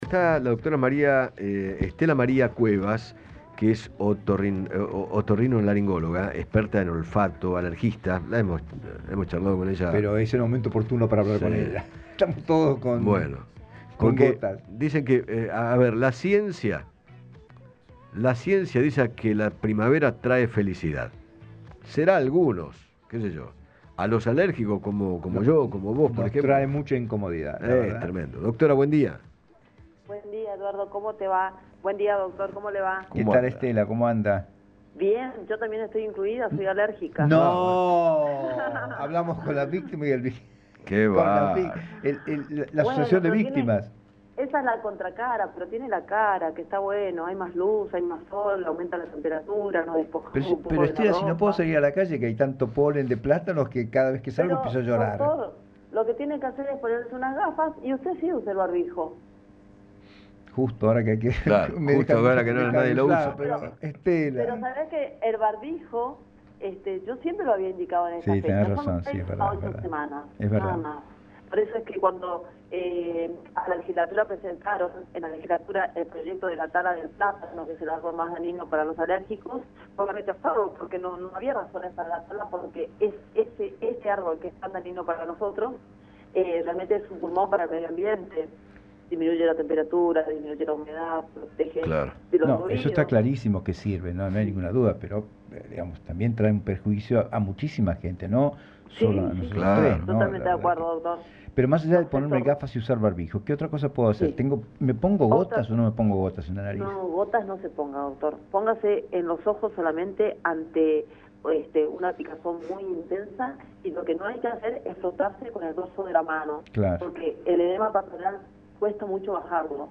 otorrinolaringóloga especialista en olfato, dialogó con Eduardo Feinmann sobre las alergias de primavera y brindó algunos consejos para prevenirlas.